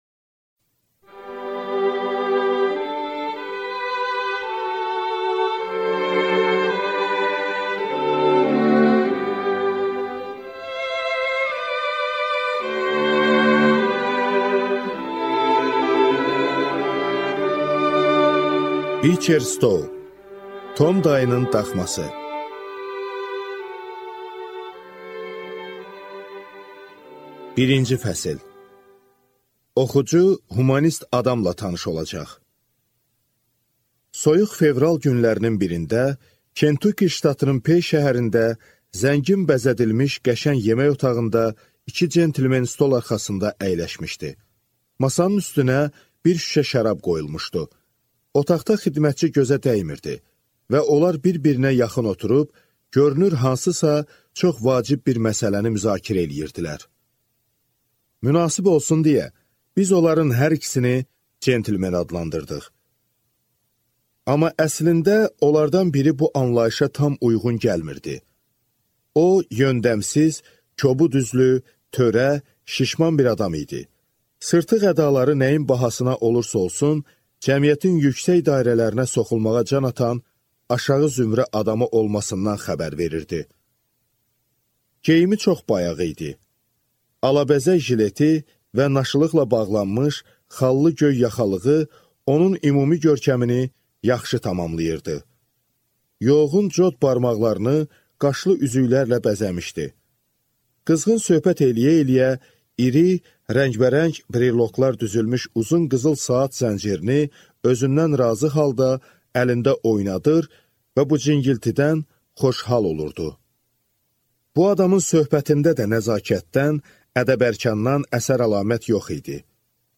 Аудиокнига Tom dayının daxması | Библиотека аудиокниг